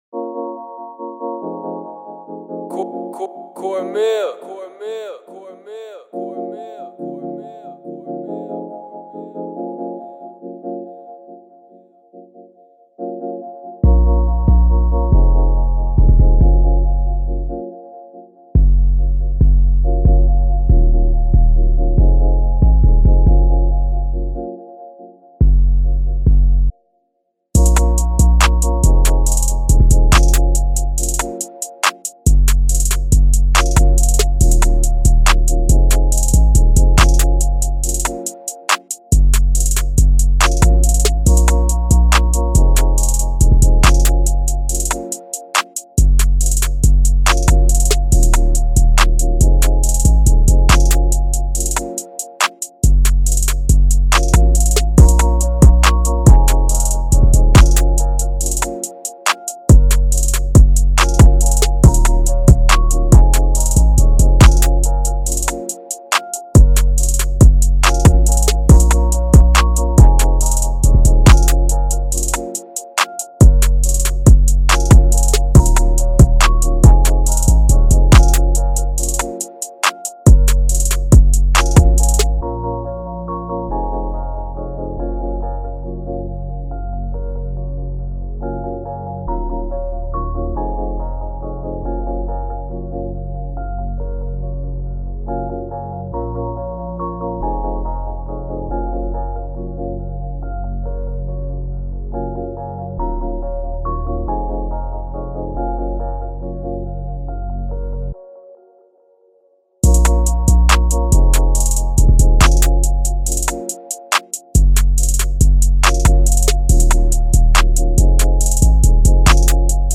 2020 in Hip-Hop Instrumentals